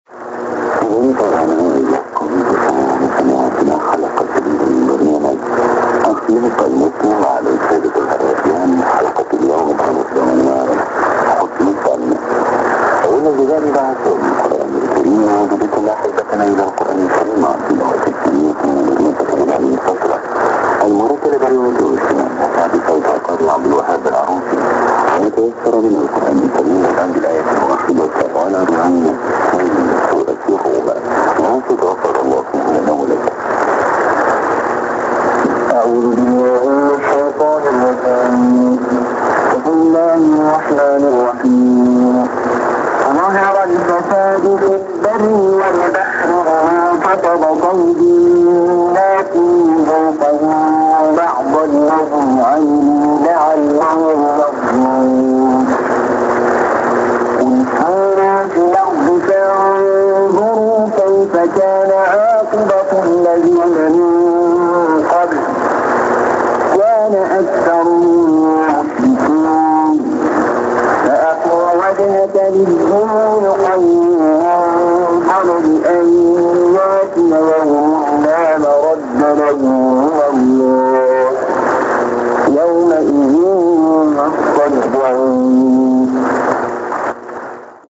Trechos de áudios de captações realizadas durante o DXCamp Lorena 2010 ocorrido durante os dias 3 e 6 de junho de 2010.
As captações foram realizadas pelos participantes do encontro, utilizando os mais variados equipamentos e antenas.